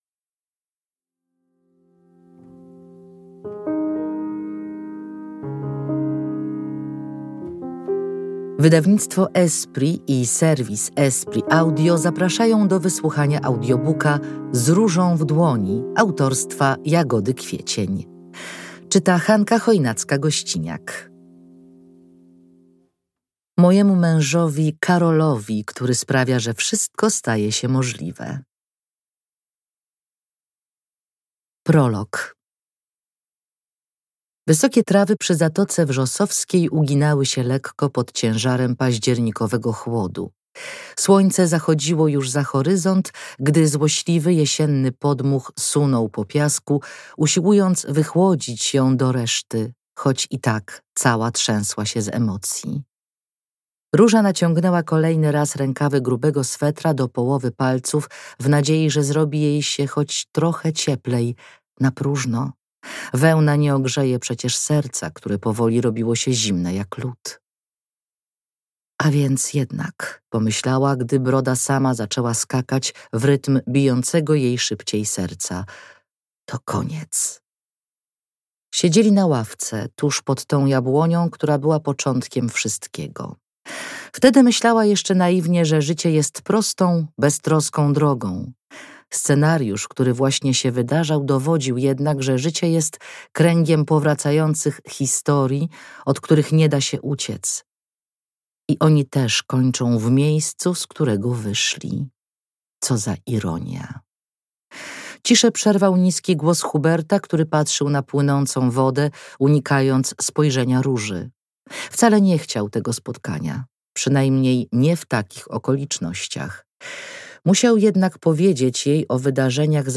Z różą w dłoni – Audiobook